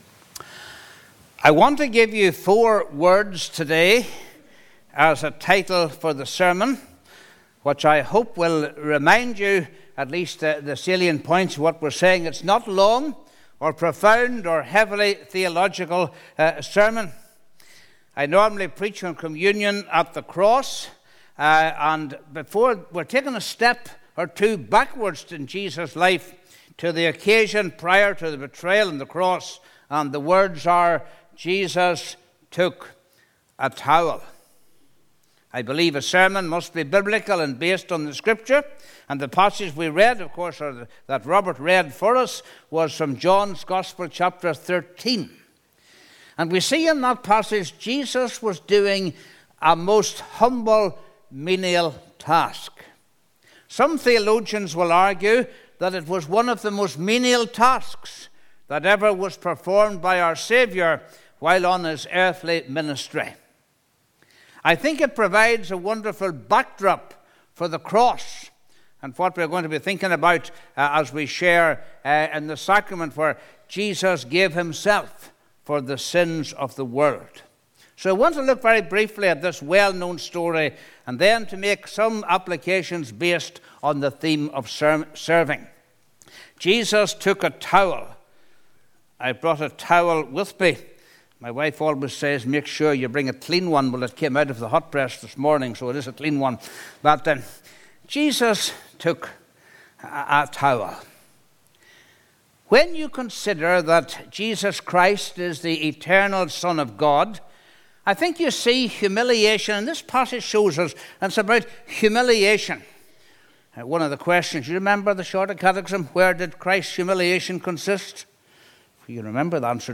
Sermon Archive - Ballywillan Presbyterian Church